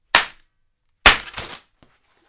拟声词 " Pop Balloon 5
描述：一个气球弹出